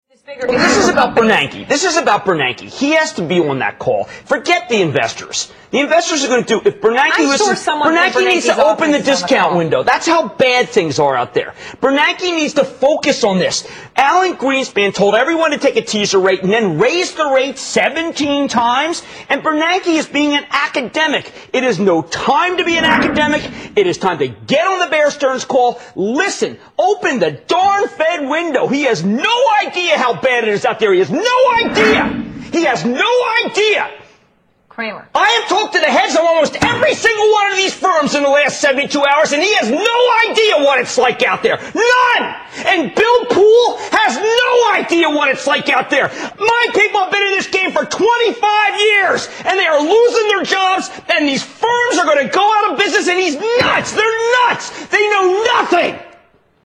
Tags: Rick Santelli Jon Stewart Jim Cramer Rants about the US economy Economic rants